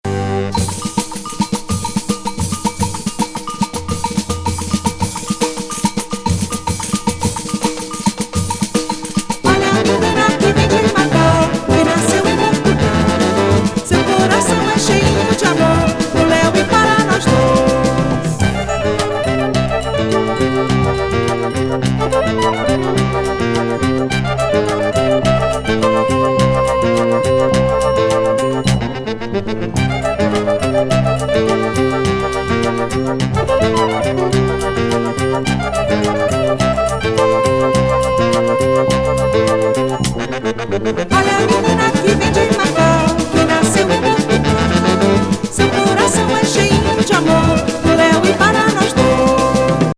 ブラジル人ジャズ・ドラマー